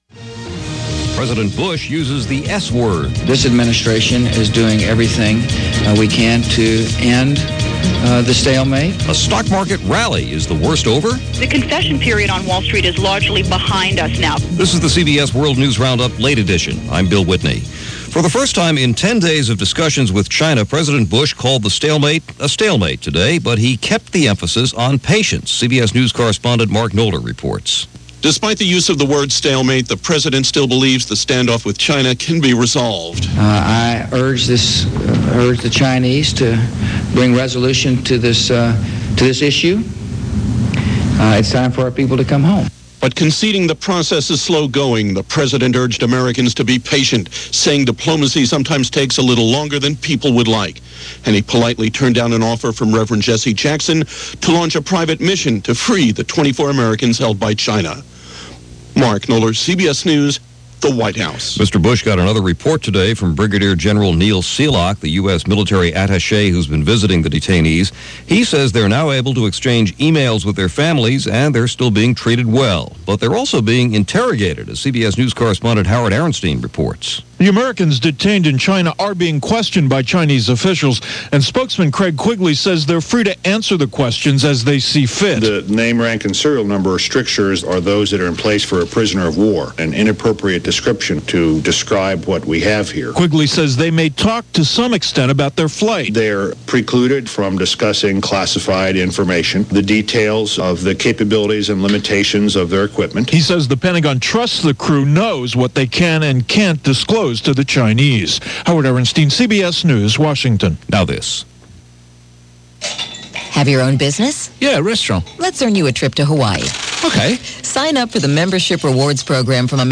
And that’s a small slice of what went on this April 10th in 2001, as reported by The CBS World News Roundup.